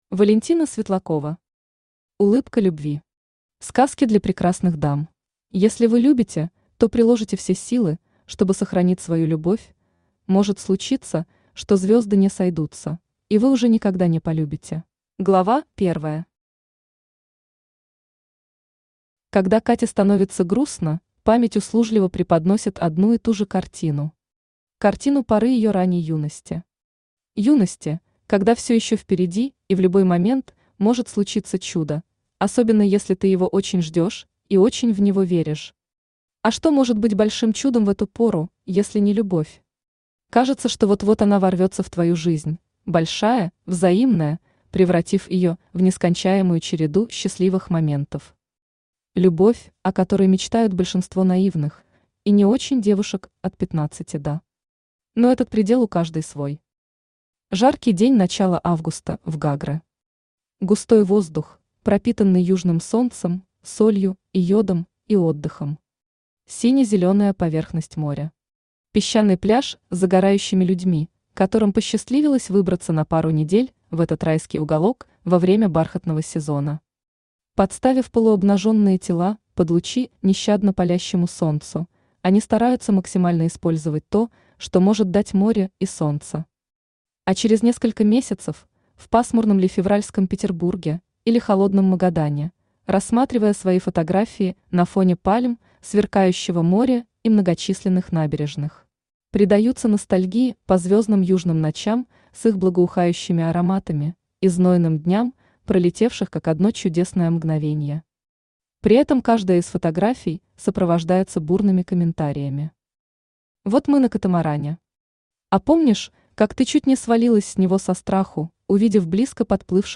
Аудиокнига Улыбка любви. Сказки для прекрасных дам | Библиотека аудиокниг
Сказки для прекрасных дам Автор Валентина Павловна Светлакова Читает аудиокнигу Авточтец ЛитРес.